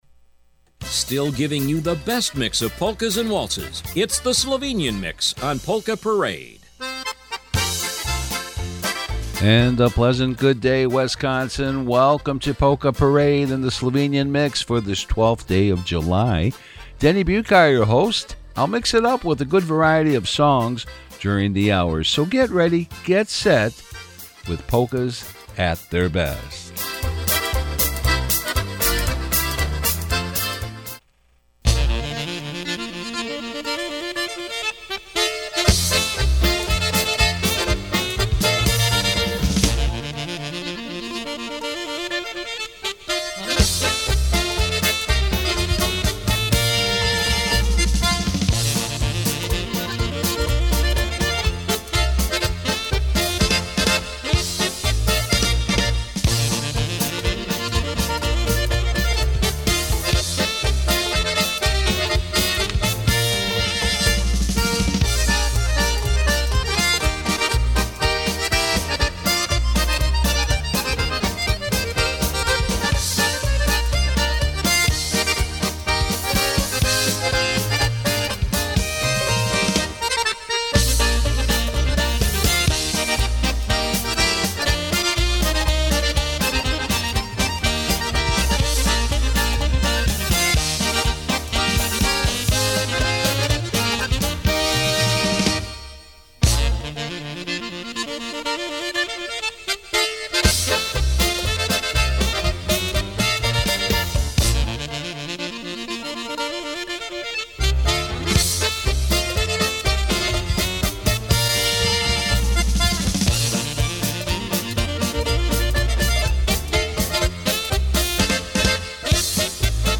Listen to the very best Slovenian, German, Polish, Duthment, Czeck and other styles of polkas and waltzes every Saturday morning or on-demand at any time.